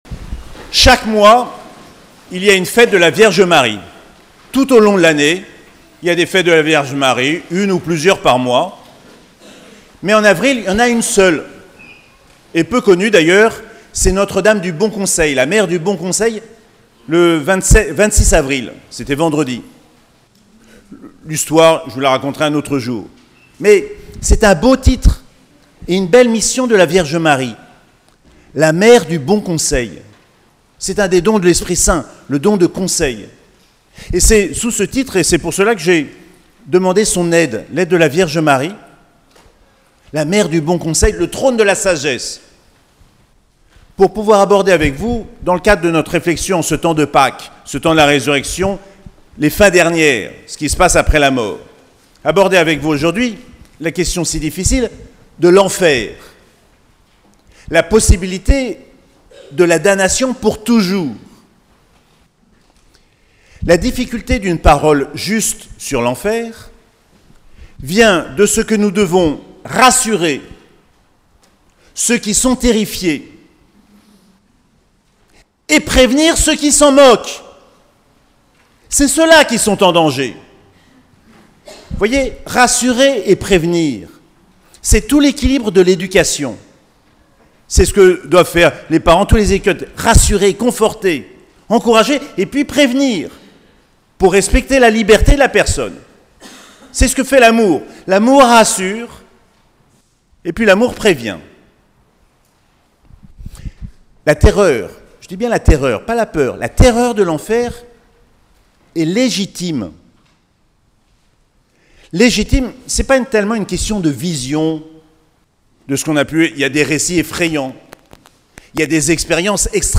5ème dimanche du Pâques - 28 avril 2024